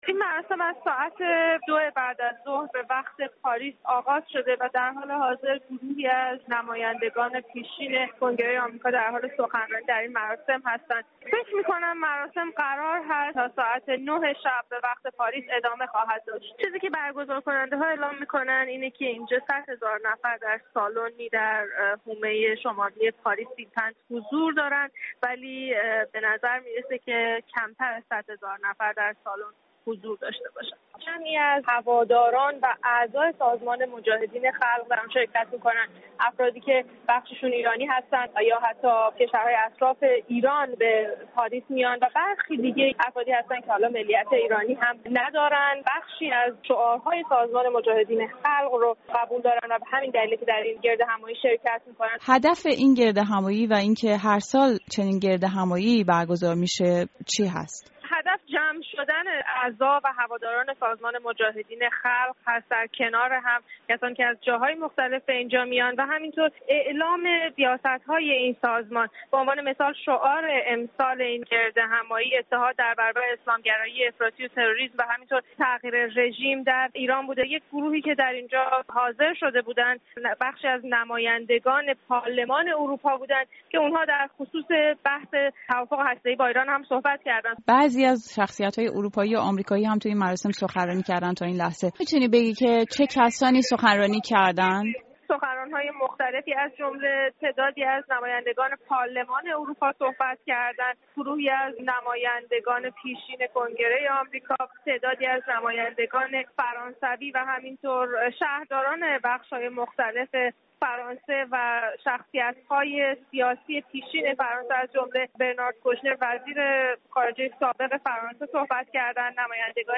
گزارش از تجمع هواداران سازمان مجاهدین خلق در پاریس